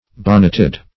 Search Result for " bonneted" : The Collaborative International Dictionary of English v.0.48: Bonneted \Bon"net*ed\, a. 1.